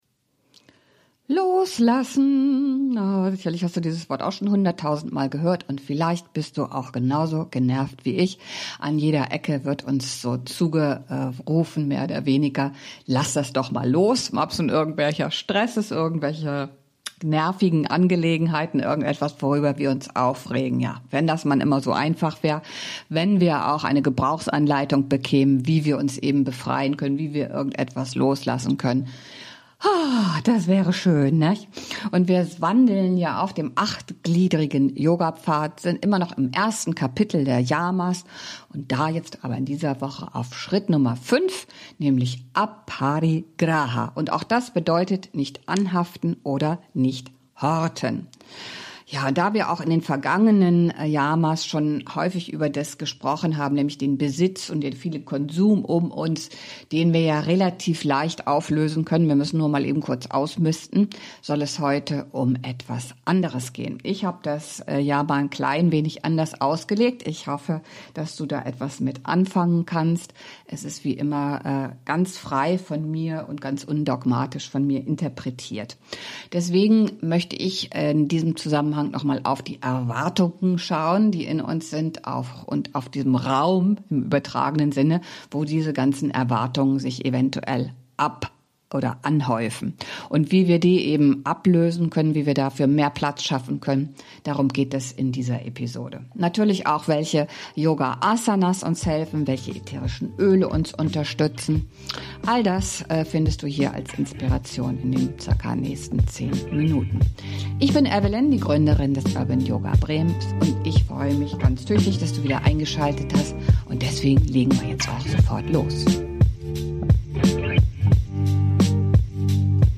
Eine ruhige, klare Episode für alle, die sich nach weniger Müssen und mehr Sein sehnen.